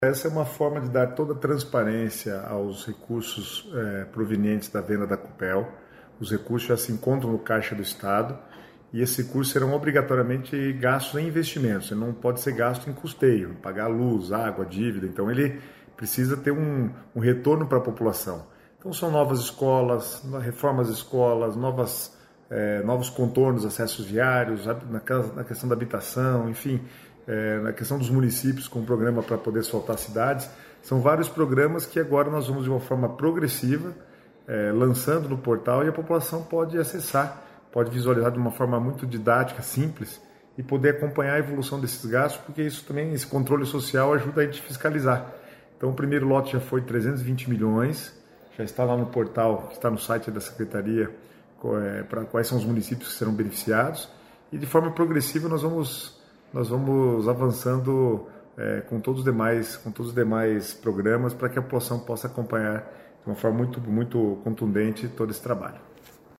Sonora do secretário Estadual do Planejamento, Guto Silva, sobre a disponibilização do painel sobre a alienação de participação acionária do Governo na Copel